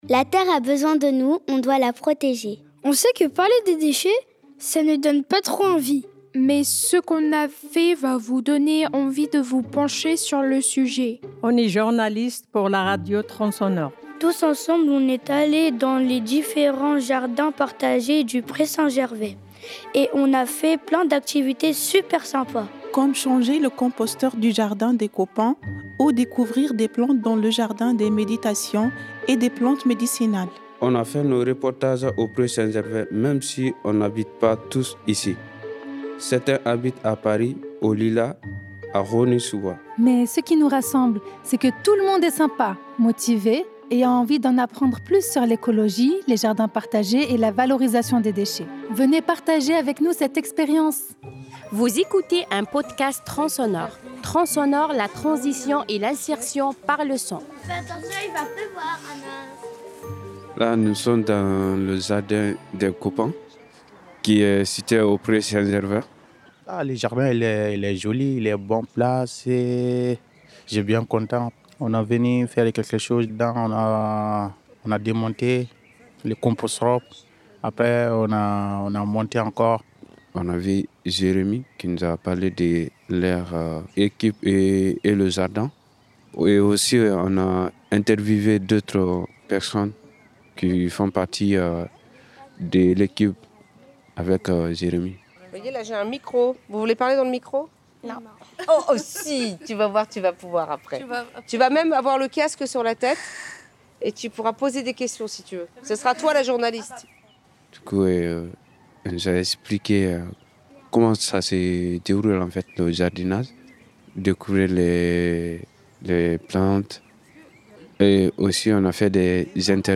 Nous sommes des enfants, des jeunes et des adultes qui habitons au Pré Saint-Gervais, mais pas que !
Découvrez nos reportages made in Le Pré !
Malgré la grosse chaleur, on a aidé à démonter l’ancien composteur et à installer le nouveau. Accompagnez-nous une nouvelle fois une exploration sonore des jardins partagés du Pré Saint-Gervais : direction le jardin des copains !